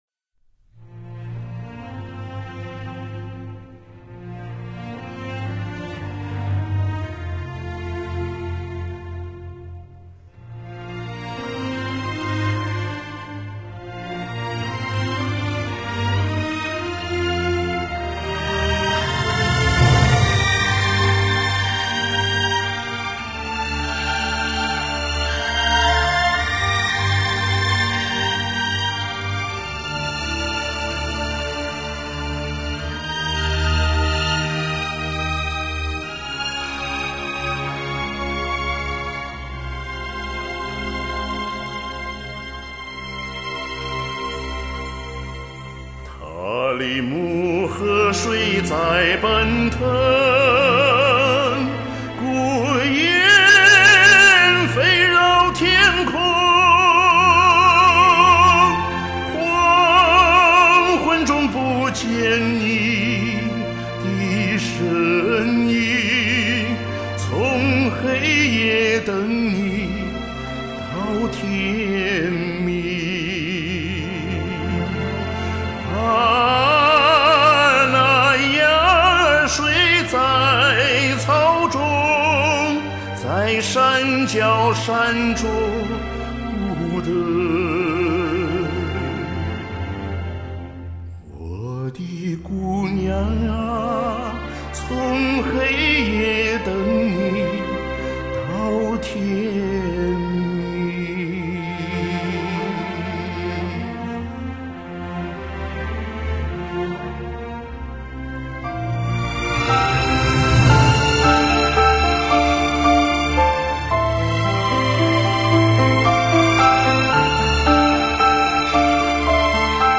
开口就震撼，激动人心啊，唱得很细腻，跌宕起伏，享受！
超强的控制力，收放自如。
激情柔情完美结合！
天生的男高音好嗓子！